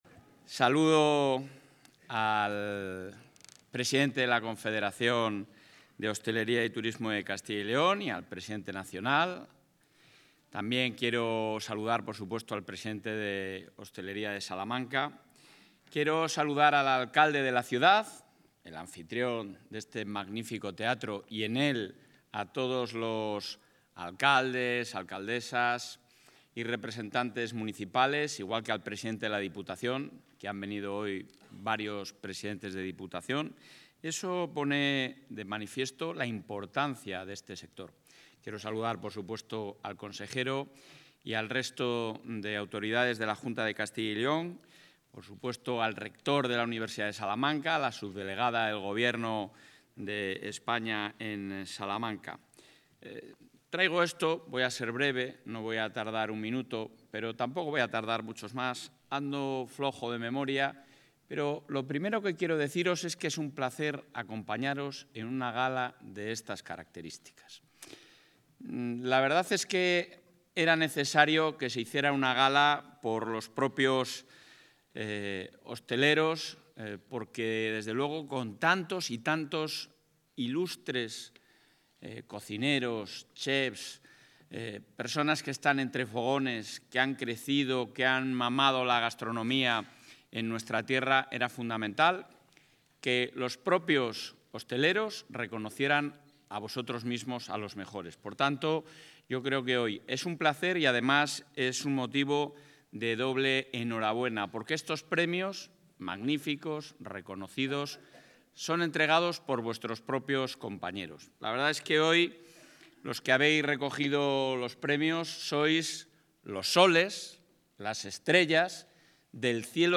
El presidente de la Junta de Castilla y León, Alfonso Fernández Mañueco, ha clausurado hoy en Salamanca la Gala HOSTURCYL,...
Intervención del presidente.